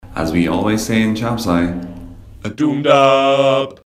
Tags: interview